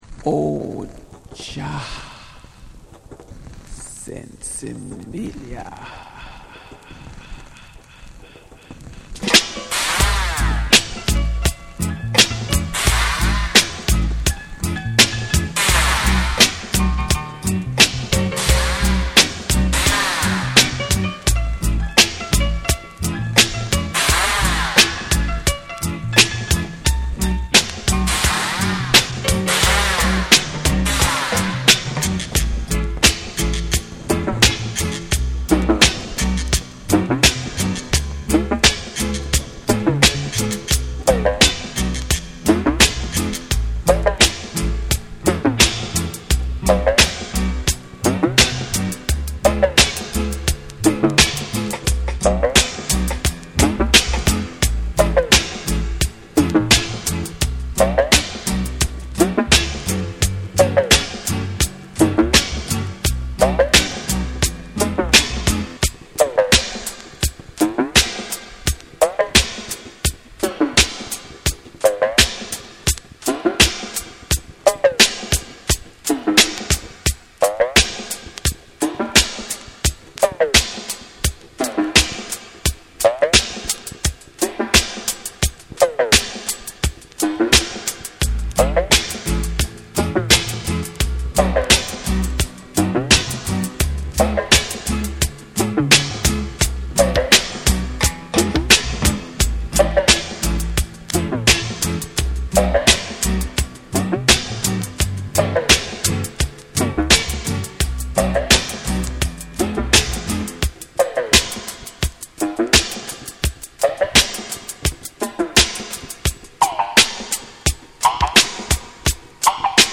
深く沈み込むベースラインと、エコー/リバーブを駆使した空間的なミックスが印象的ダブ・アルバム。
ジャマイカ盤特有のチリノイズが入ります。